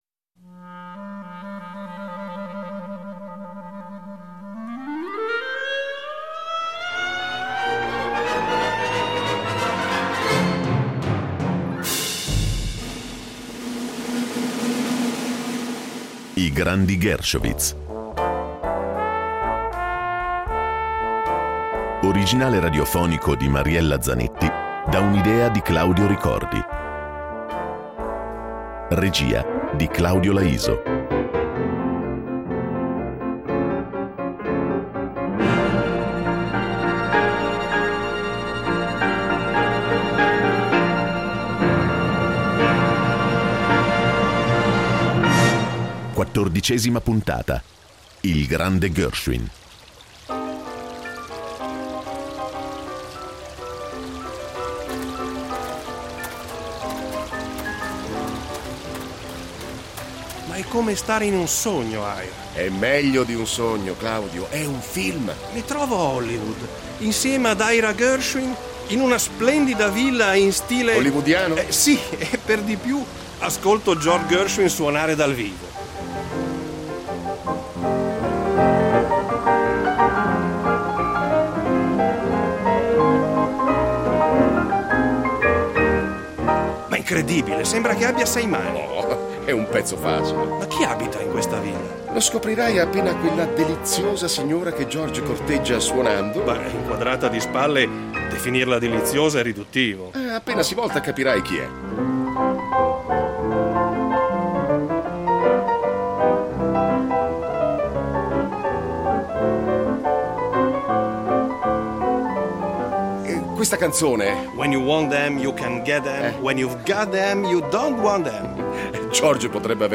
Rete Due propone il riascolto di un radiodramma dedicato allo straordinario rapporto tra Ira e George Gershowitz.